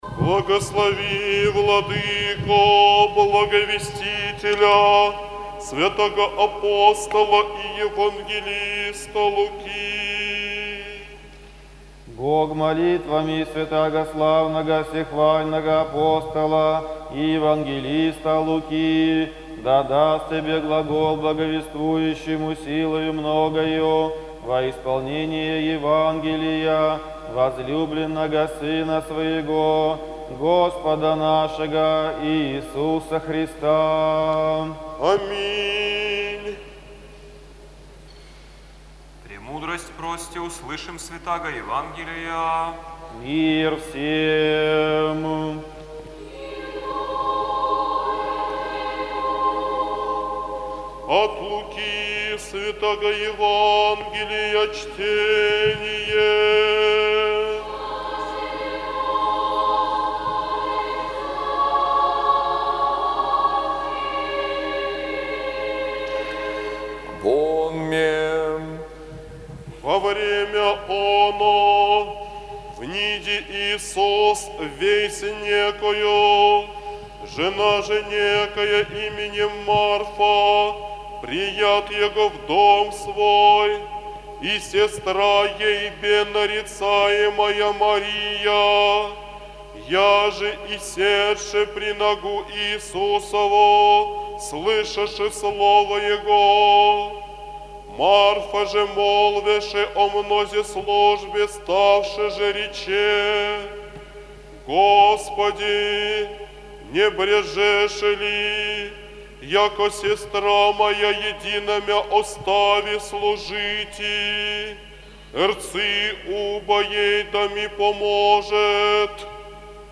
ЕВАНГЕЛЬСКОЕ ЧТЕНИЕ НА ЛИТУРГИИ
Евангелие-от-Луки-Празд.-Казанской-иконы-4.11.mp3